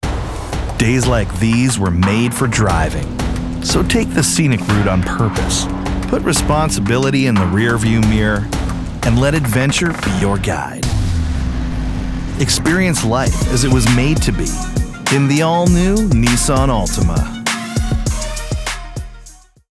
A Robust Baritone Full of Character
Car Commercial Demo - English